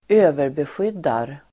Ladda ner uttalet
Uttal: [²'ö:verbesjyd:ar]